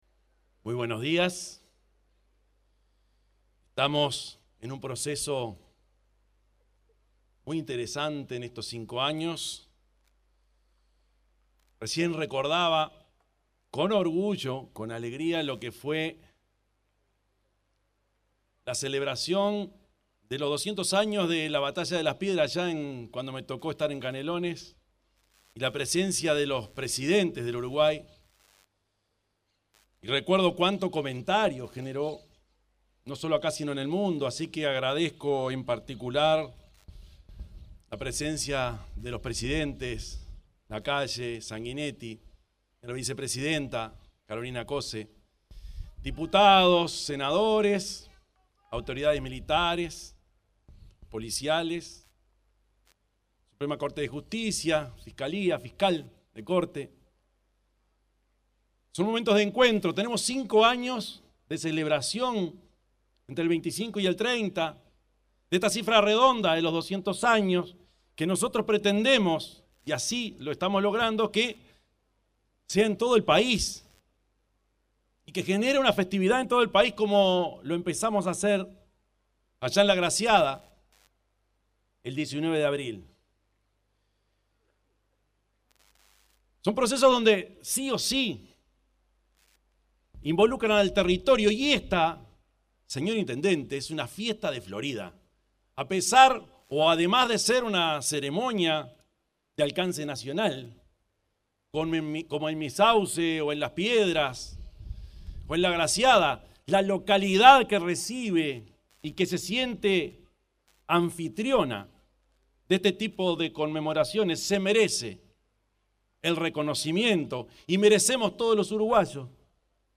Palabras del presidente Yamandú Orsi en celebración de Declaratoria de la Independencia
Palabras del presidente Yamandú Orsi en celebración de Declaratoria de la Independencia 25/08/2025 Compartir Facebook X Copiar enlace WhatsApp LinkedIn Este 25 de agosto se realizó, en la Piedra Alta del departamento de Florida, el acto conmemorativo de los 200 años de la Declaratoria de la Independencia, definida como el inicio del proceso independentista de la nación. En la oportunidad, se expresó el presidente de la República, Yamandú Orsi.